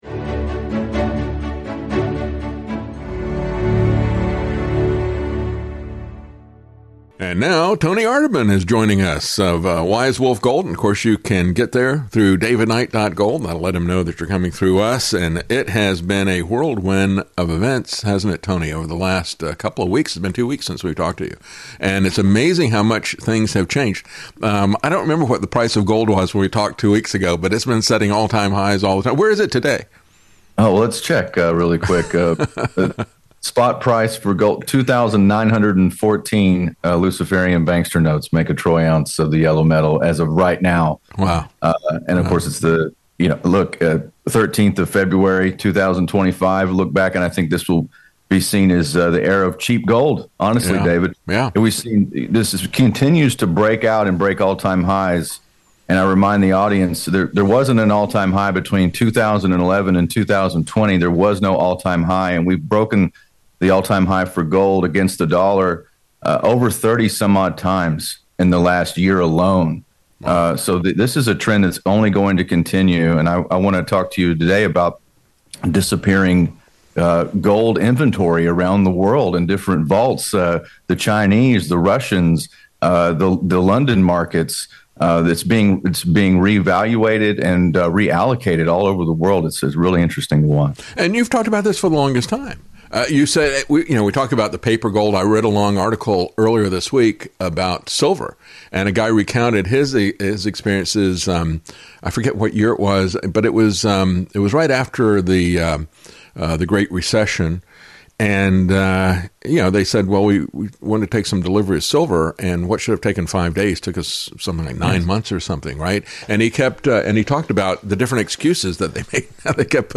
INTERVIEW: Gold Rush 2025 Empty Vaults Chaos - The Great Repatriation and the End of Paper Money!